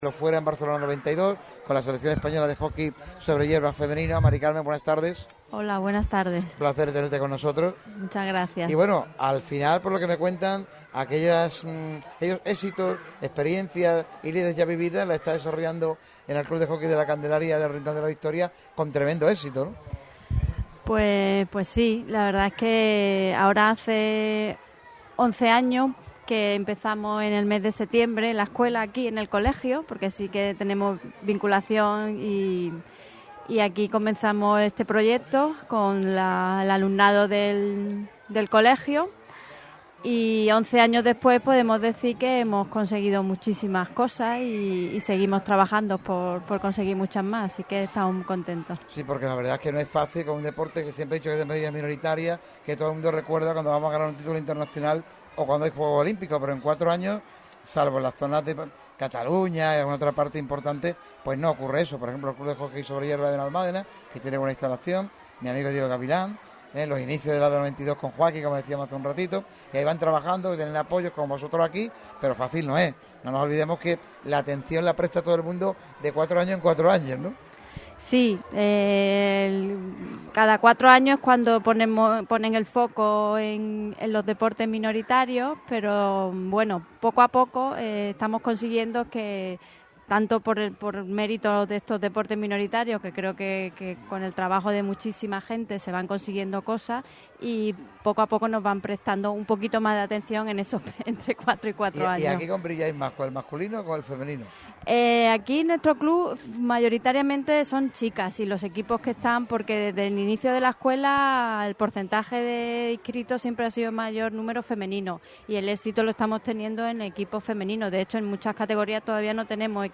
Un programa celebrado en el CEIP Nuestra Señora de La Candelaria con la colaboración principal del Rincón de la Victoria.
Mari Carmen Barea, Campeona Olímpica de Hockey en los Juegos Olímpicos de Barcelona 92 y actual dirigente del Club de Hockey La Candelaria, visitó el micrófono rojo de Radio MARCA Málaga para hablar de la actualidad del deporte del ‘stick’ en la provincia.